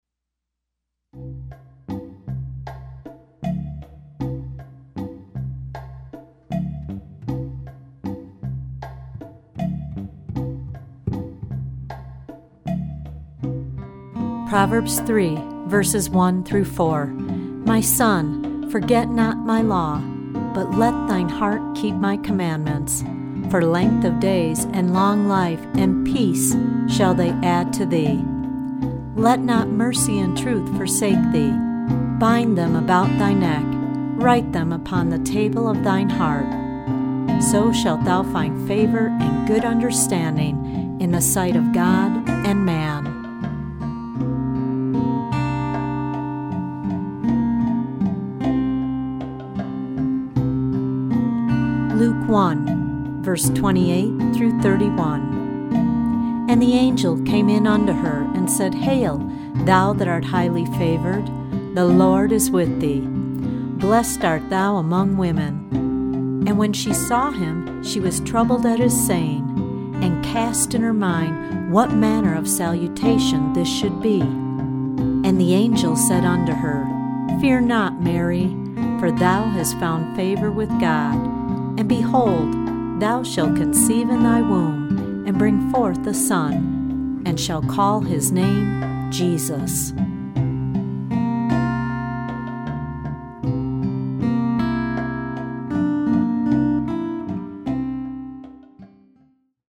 original anointed instrumental music on six CD’s.